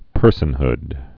(pûrsən-hd)